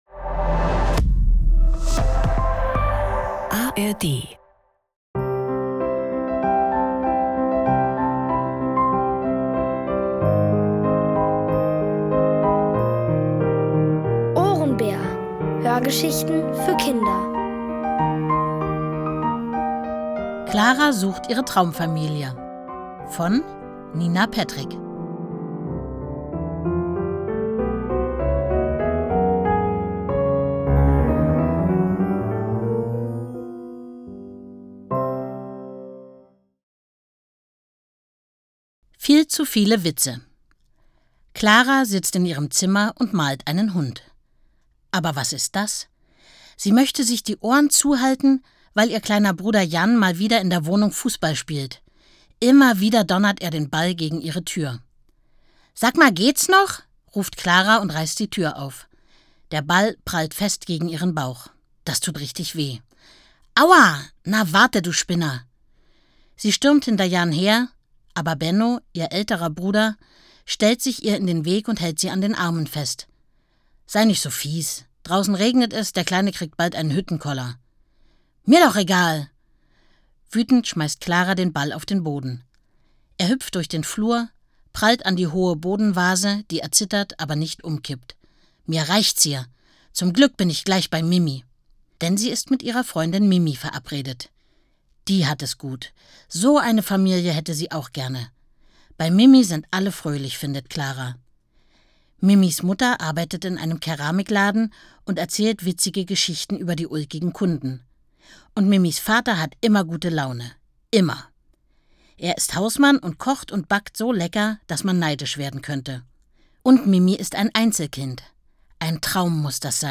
Clara sucht ihre Traumfamilie | Die komplette Hörgeschichte! ~ Ohrenbär Podcast